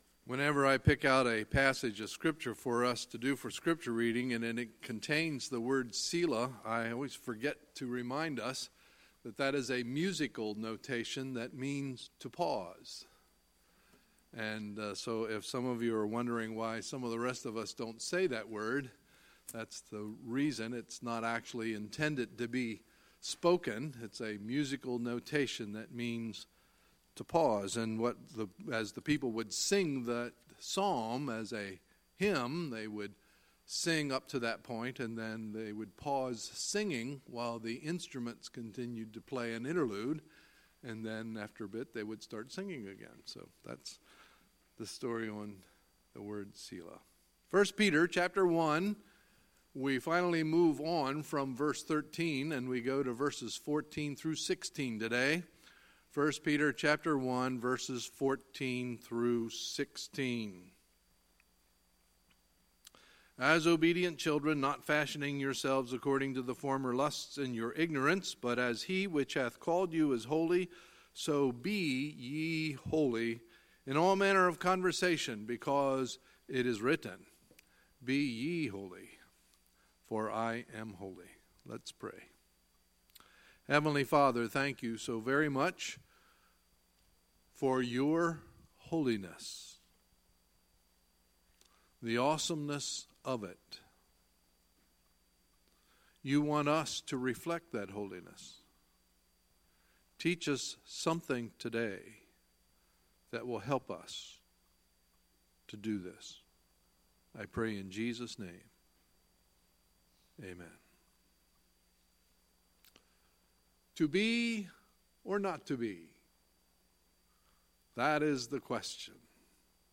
Sunday, March 4, 2018 – Sunday Morning Service